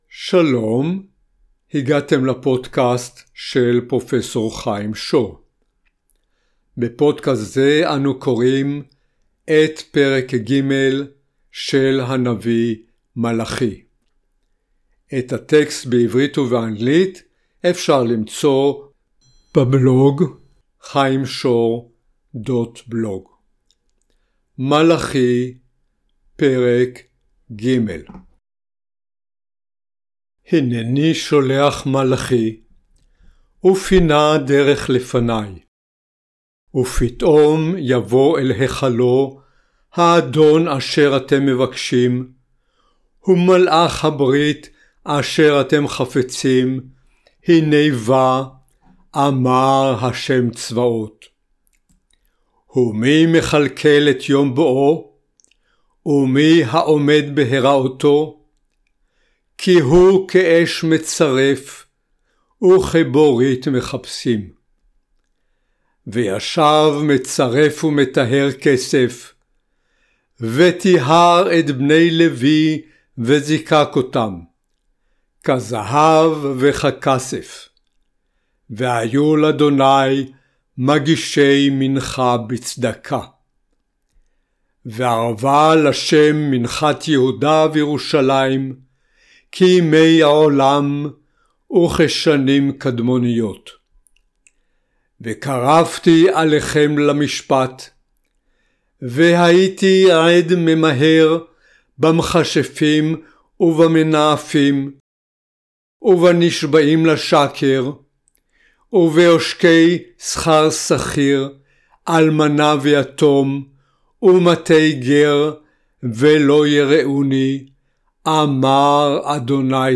End-time scenarios, as described by prophet Malachi, Chapter 3, read in native Hebrew (a PDF file with the text, Hebrew/English, available for download).